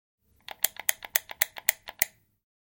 На этой странице собраны звуки электронных сигарет: шипение, бульканье, парение и другие эффекты.
Нажимаем несколько раз кнопку включения вейпа